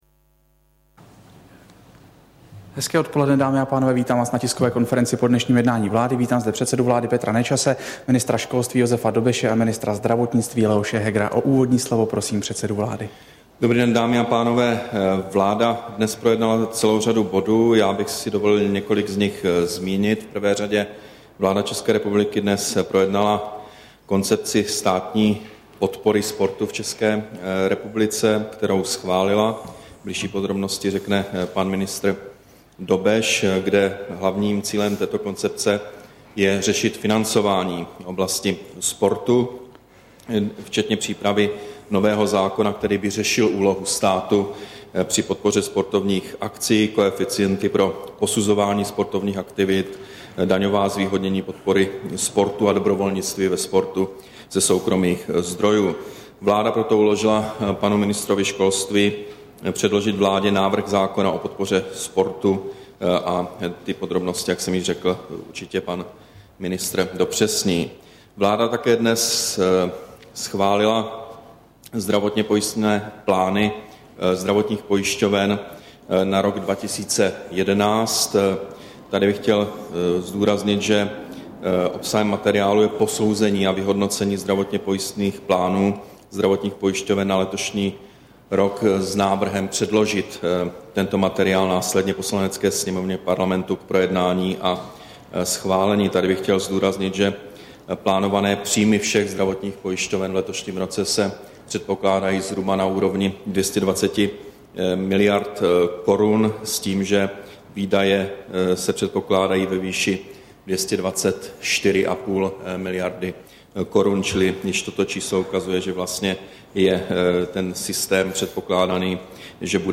Tisková konference po jednání vlády, 9. března 2011